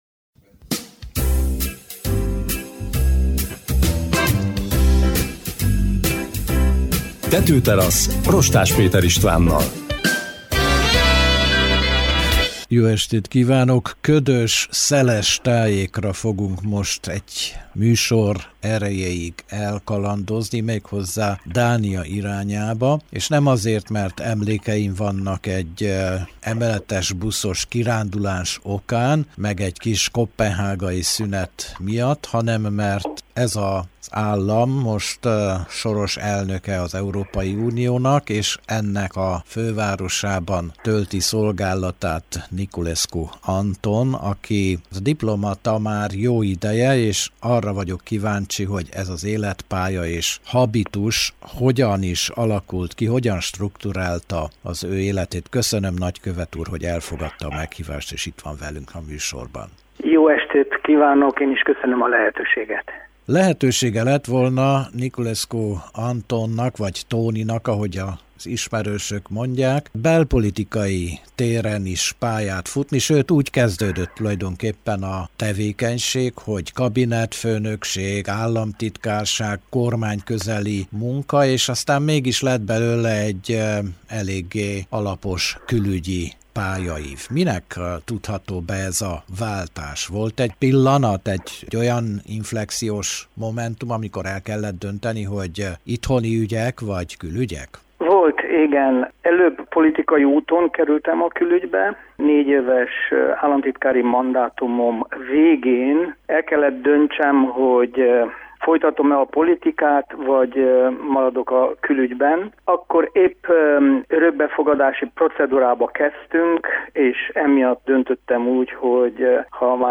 Niculescu Anton jelentkezett be a Tetőteraszra Koppenhágából.